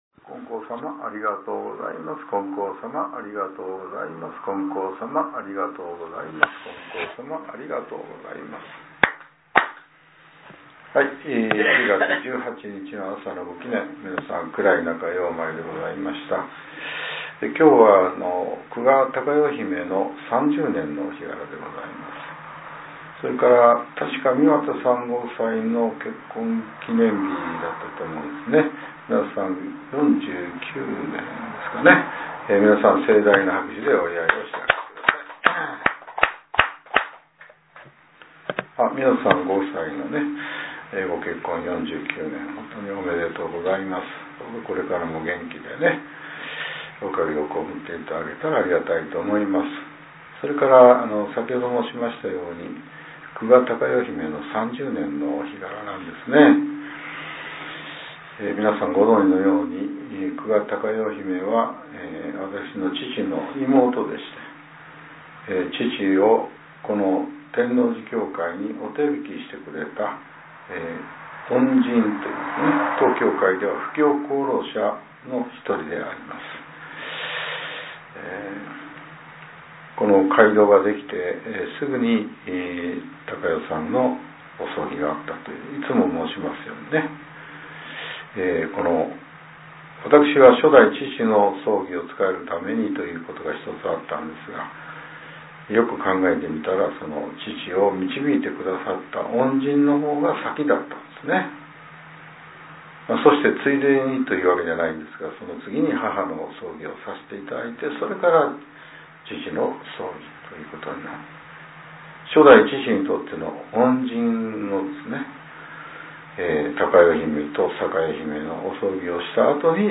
令和７年１０月１８日（朝）のお話が、音声ブログとして更新させれています。